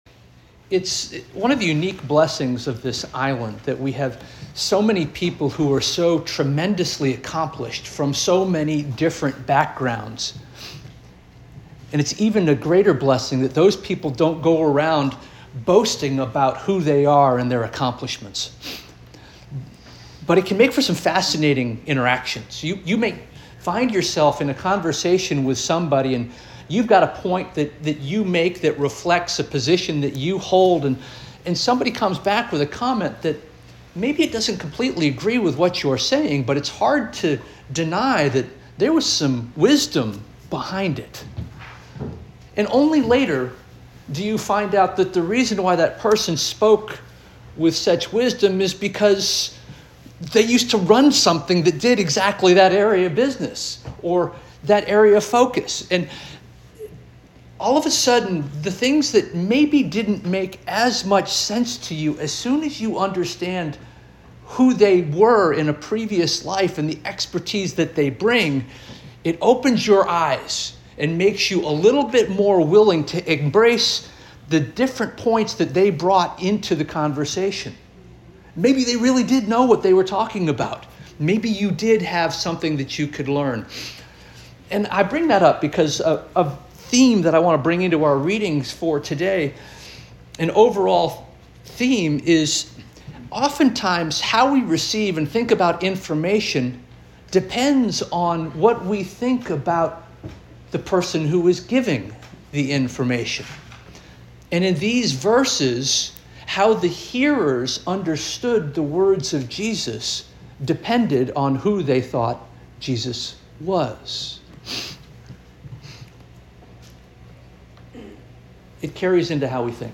January 25 2026 Sermon - First Union African Baptist Church